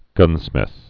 (gŭnsmĭth)